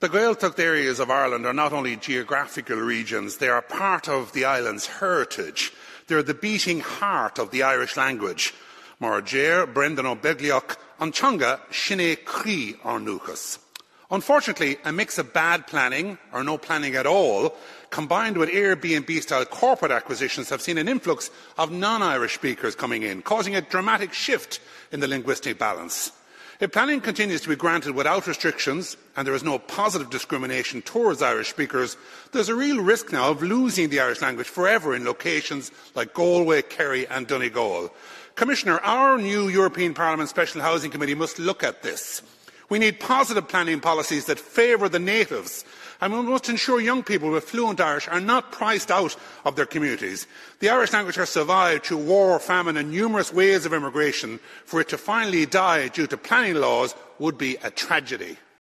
Speaking in the European Parliament, he argued that the delay threatens both housing provision and the survival of the Irish language in regions like Donegal.